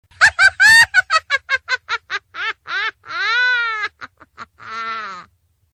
Risada Bruxa Feiticeira
Risada da bruxa feiticeira (hahahaha).
risada-bruxa-feiticeira.mp3